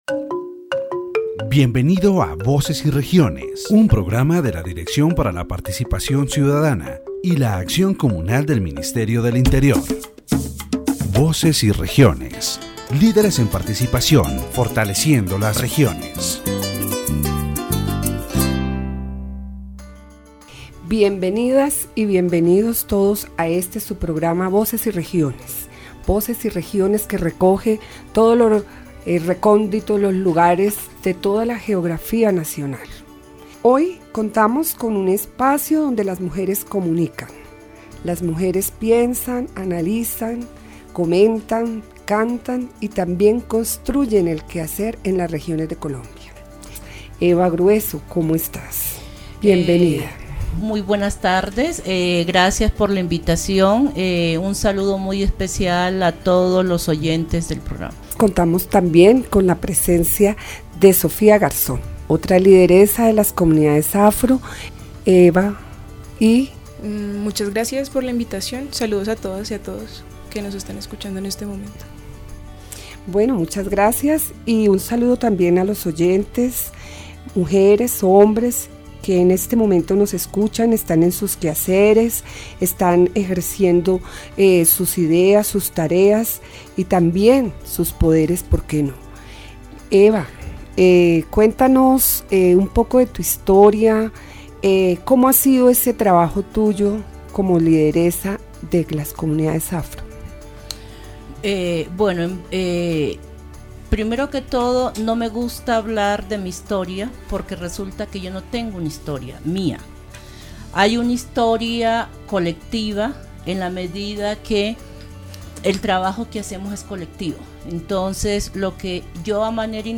In this radio program, the active participation of women and young people in Afro-Colombian movements is addressed, highlighting their work in the construction of collective identity and the fight for the rights of Afro communities.